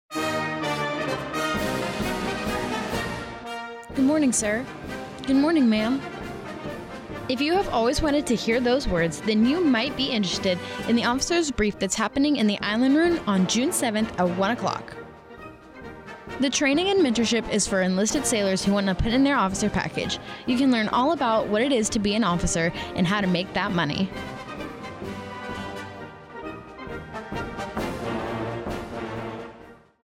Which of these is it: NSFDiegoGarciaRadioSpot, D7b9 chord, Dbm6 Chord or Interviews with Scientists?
NSFDiegoGarciaRadioSpot